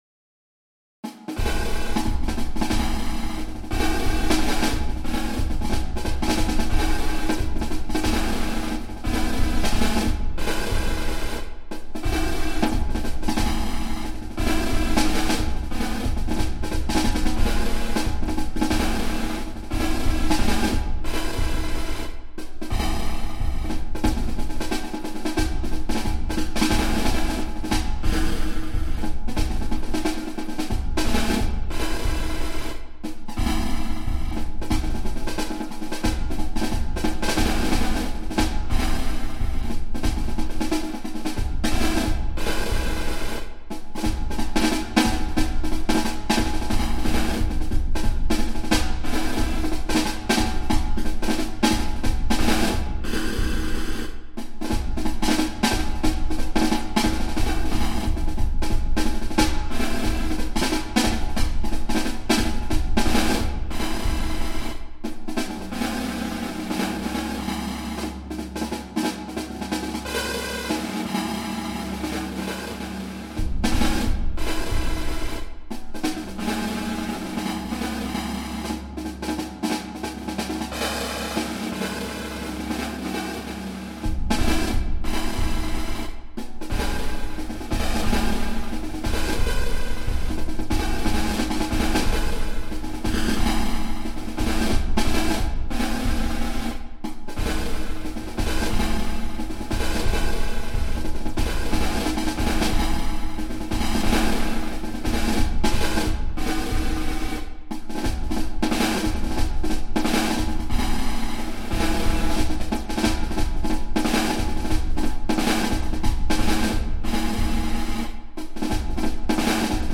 Ongestemd Marcherend Slagwerk
Snare drum Field drum Bass drum